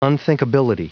Prononciation du mot unthinkability en anglais (fichier audio)
Prononciation du mot : unthinkability